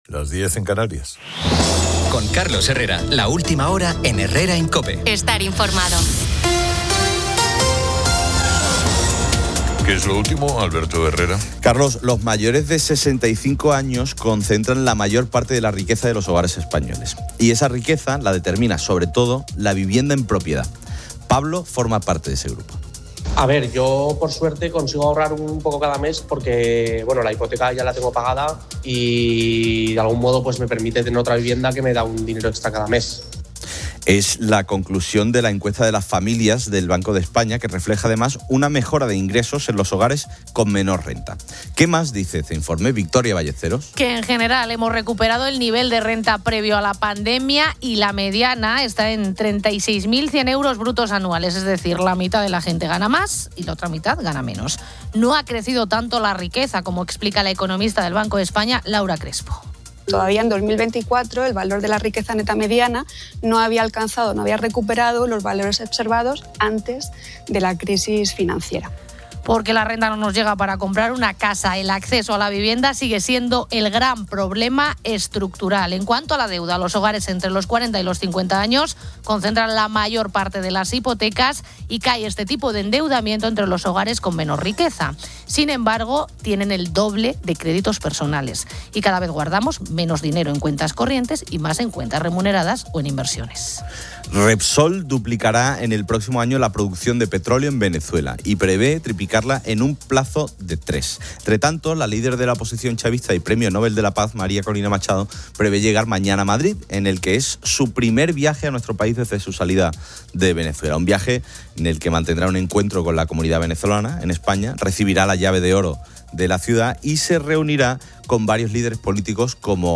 En deportes, el Real Madrid se despide de la Champions tras caer en Múnich, lo que sugiere un año en blanco, mientras el Atlético de Madrid avanza a semifinales. Una entrevista con Gente de Zona revela su infancia humilde en Cuba y la dura realidad de la dictadura, la falta de libertad y la represión, expresando su exilio y la esperanza de un cambio para su país. Finalmente, se aborda el impacto de la tecnología en la salud, alertando sobre la obsesión por los datos de los relojes inteligentes, que, aunque útiles para detectar problemas como arritmias, pueden generar ansiedad y dependencia, desvirtuando la intuición del propio cuerpo.